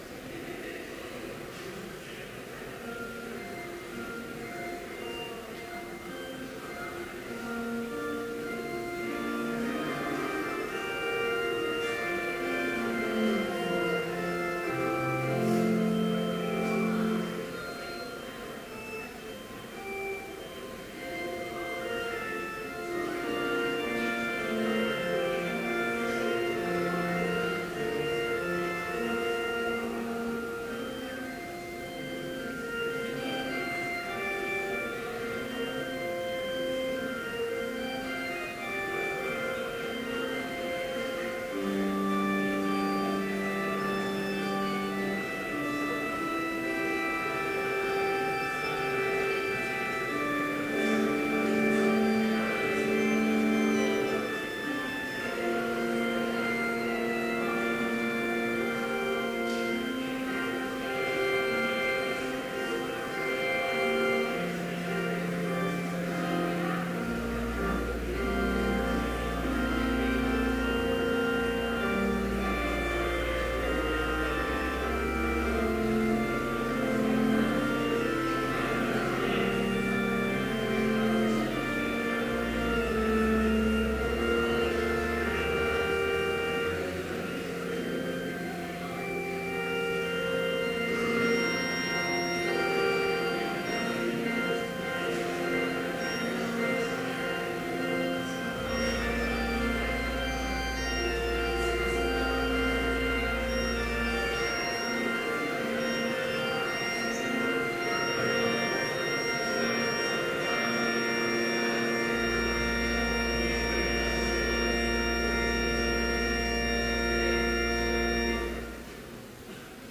Complete service audio for Chapel - May 7, 2014